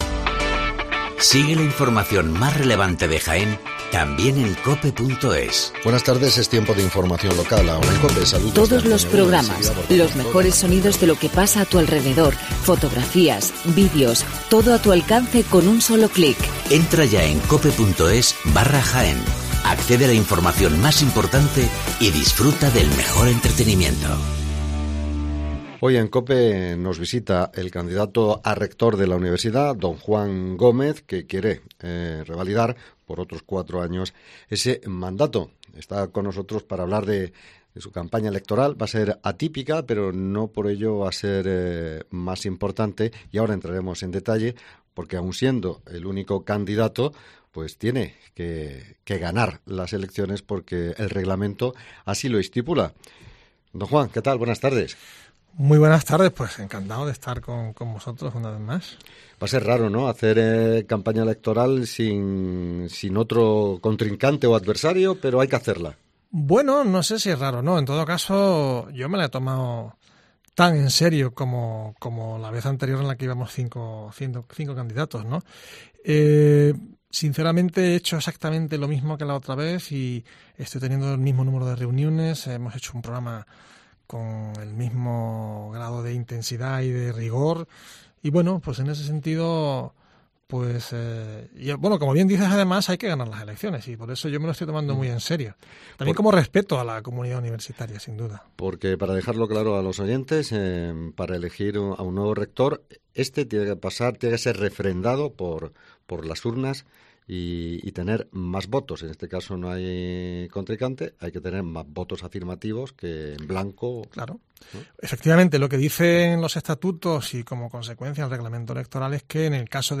Hoy ha estado COPE Jaen